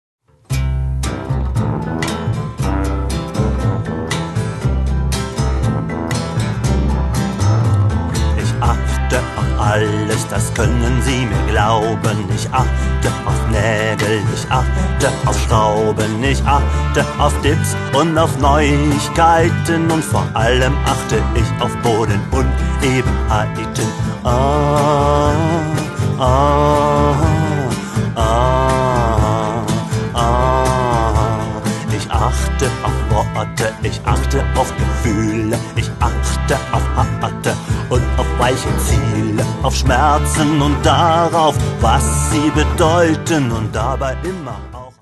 Ich mache deutschsprachige Lieder zur Gitarre.